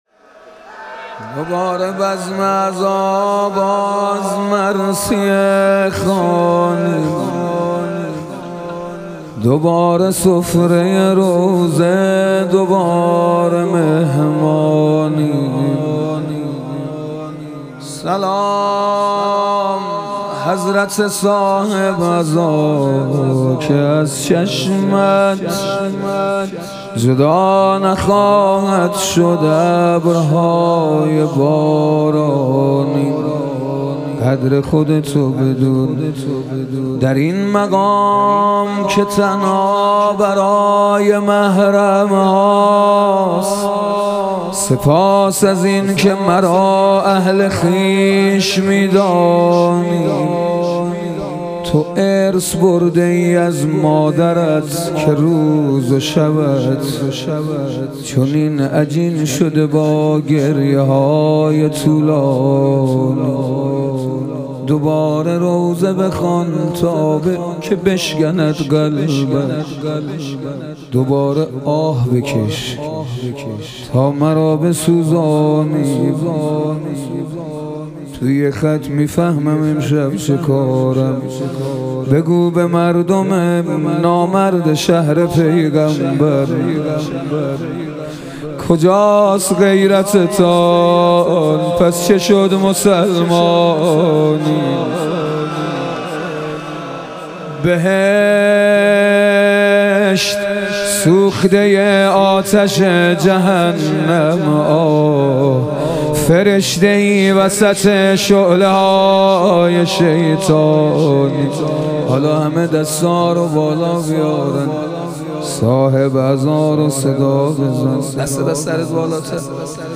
ایام فاطمیه دوم - روضه